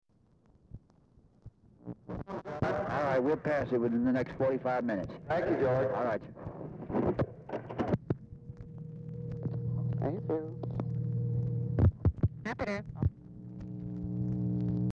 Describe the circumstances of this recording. Format Dictation belt Location Of Speaker 1 Oval Office or unknown location